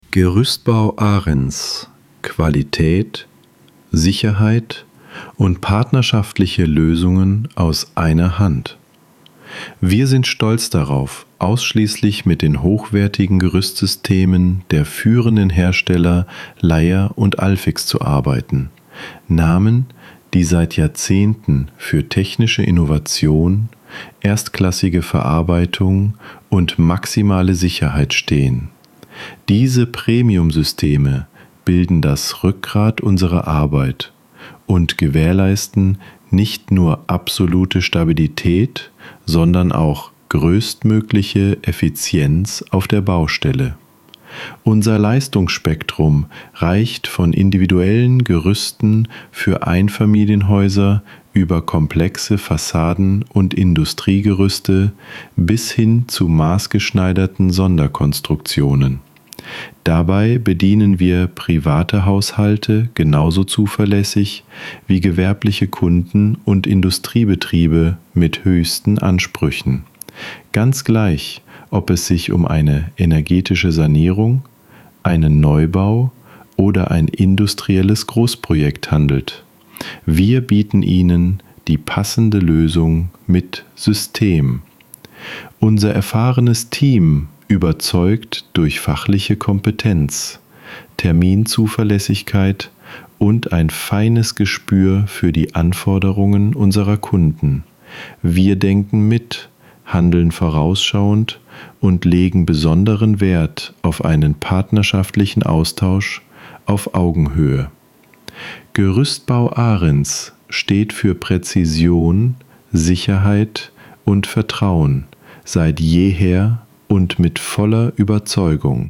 Text vorlesen – Qualität bei Gerüstbau Arens